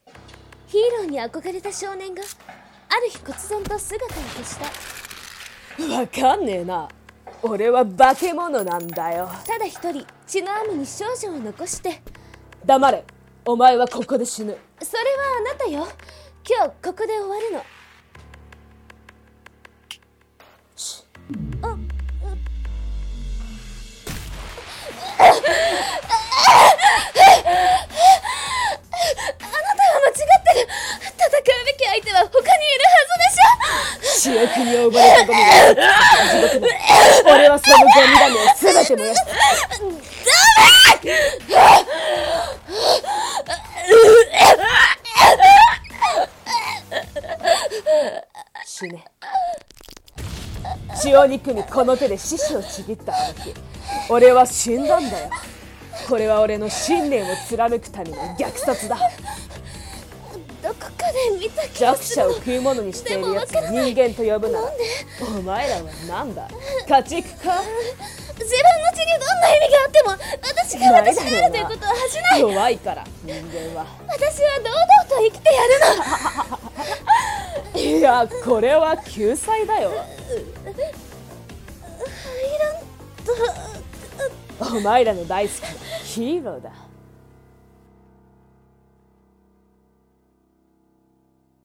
声劇】Heiland